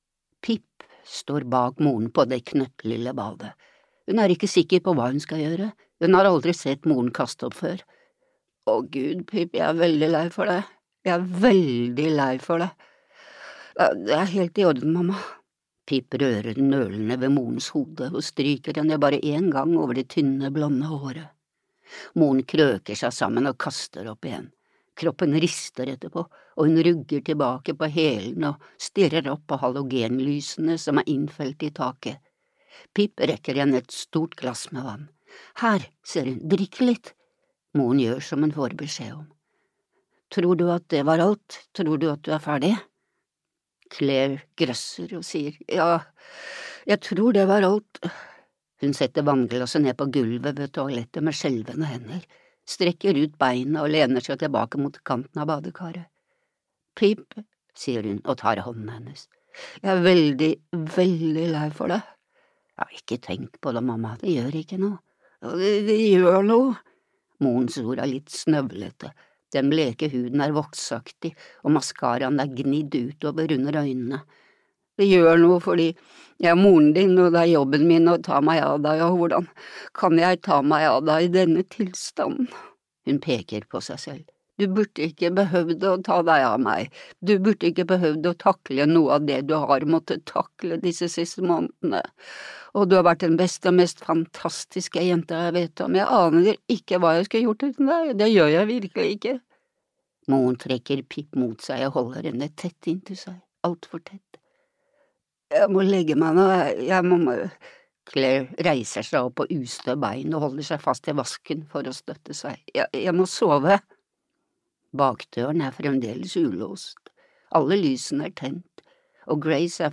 Jentene i parken (lydbok) av Lisa Jewell